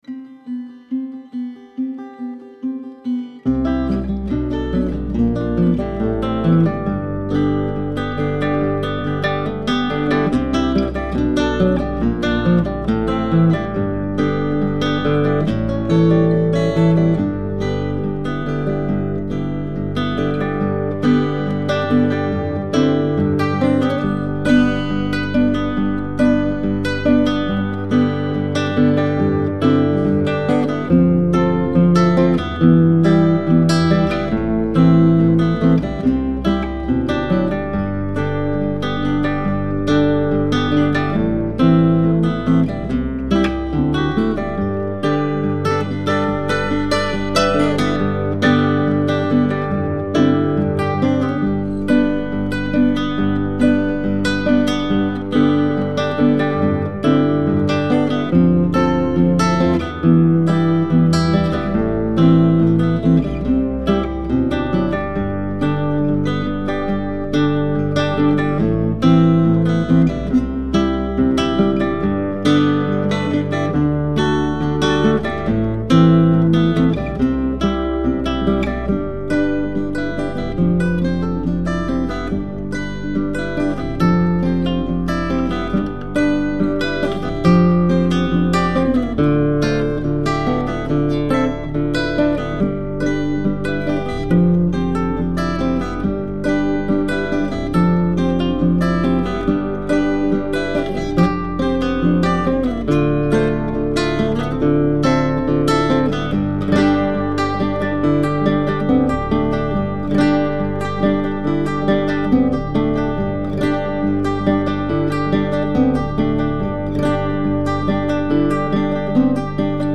With bells & whistles: